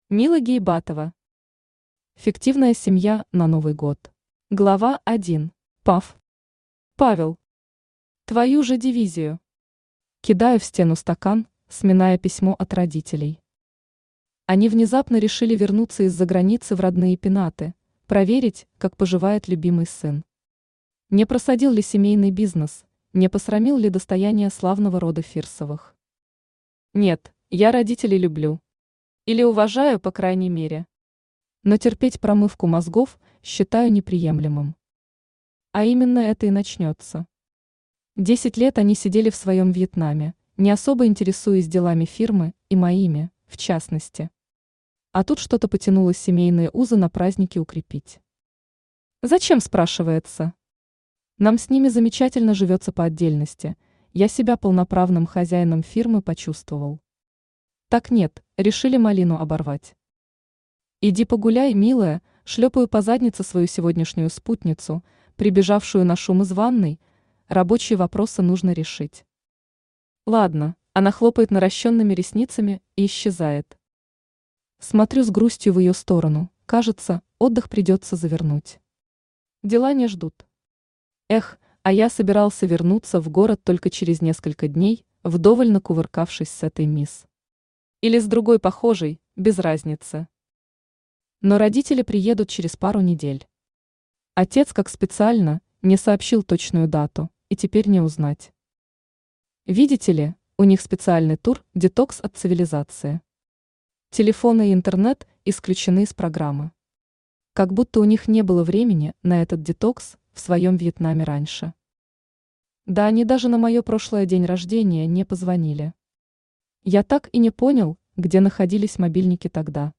Аудиокнига Фиктивная семья на Новый год | Библиотека аудиокниг